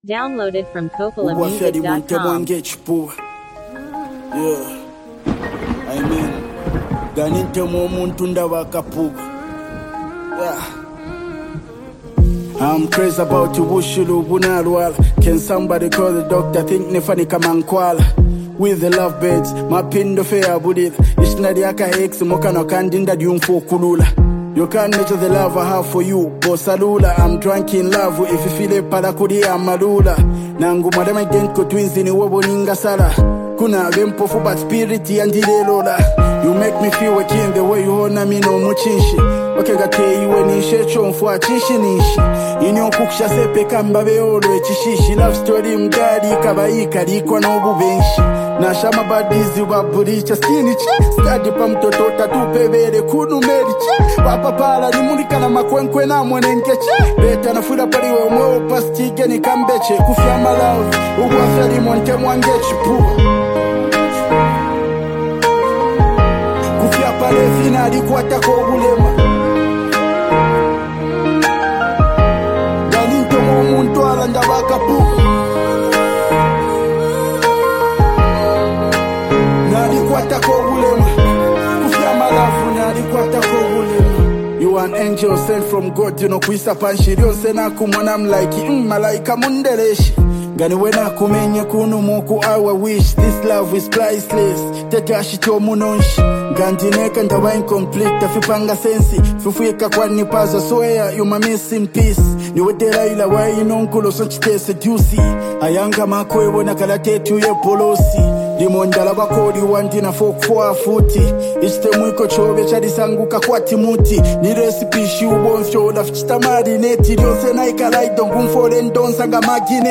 a bold and energetic song that carries a strong street vibe
making the track both a club banger and a statement piece.